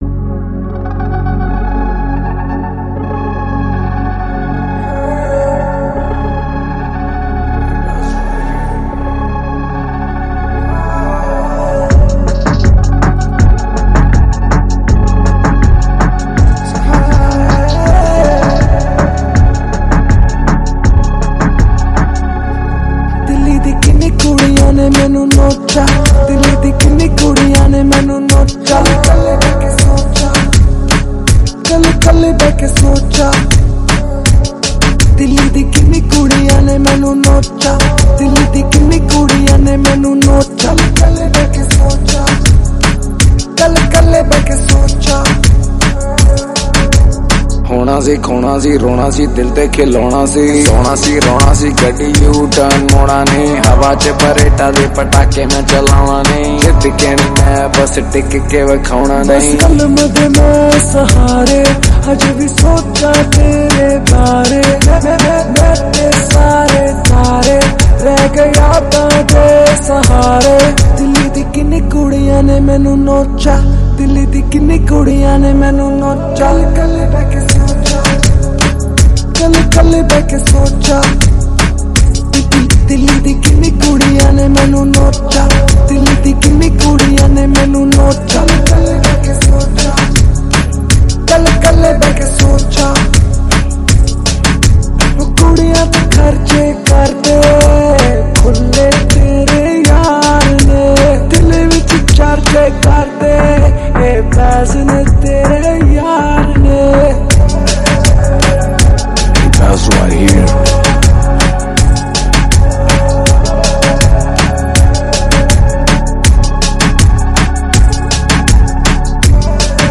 Category: Single Songs